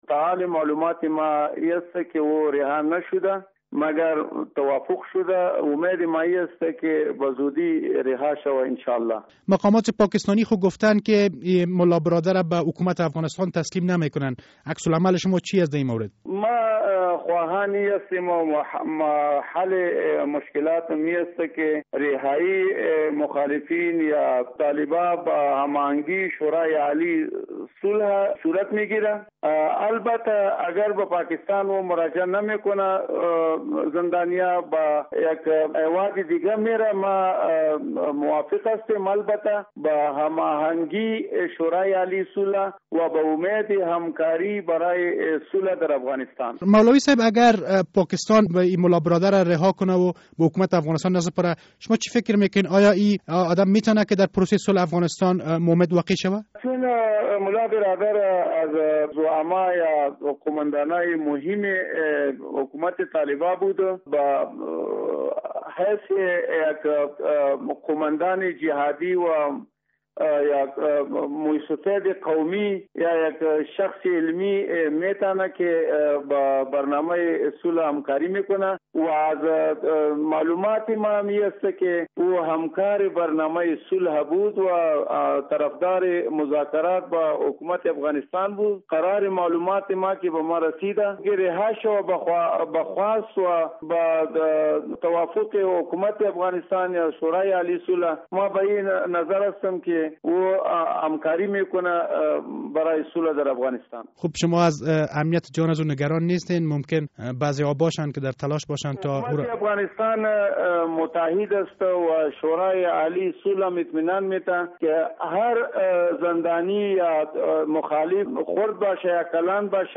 مصاحبه: ملا برادر پس از آزادی آماده همکاری در پروسه صلح افغانستان است